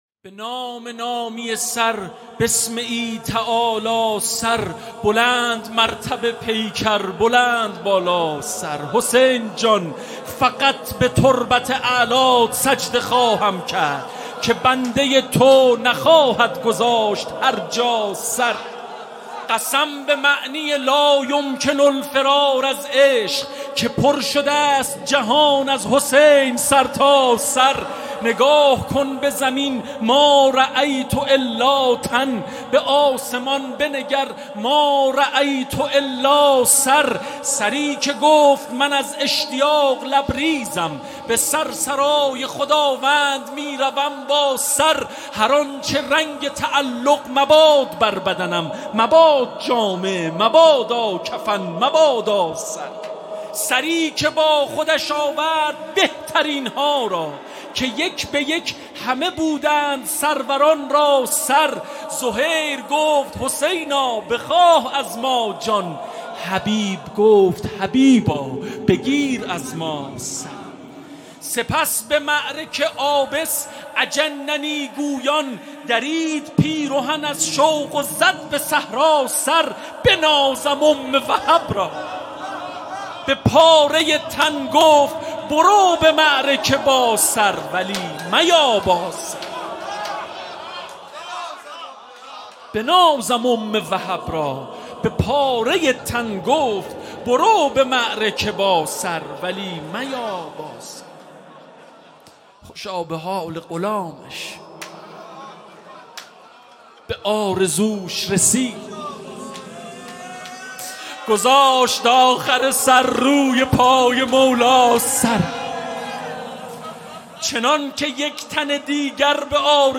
شهادت امام جواد (ع) هیئت لواء الزینب طهران